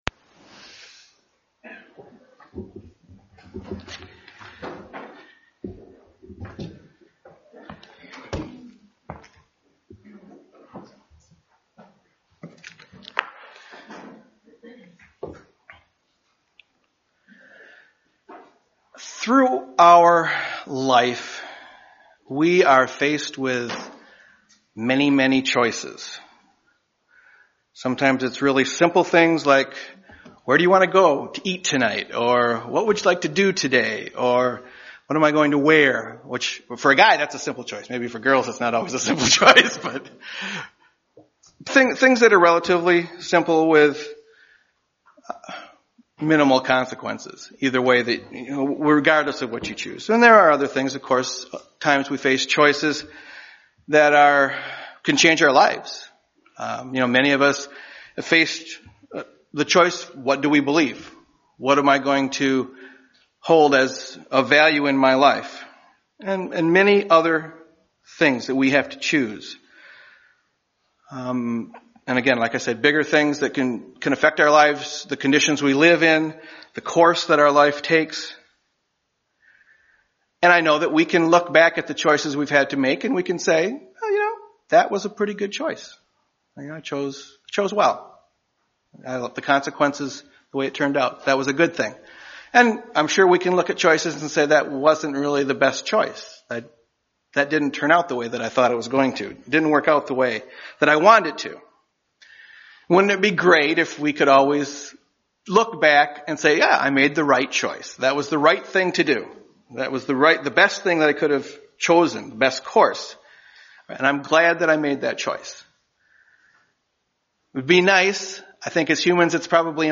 UCG Sermon Studying the bible?
Given in Grand Rapids, MI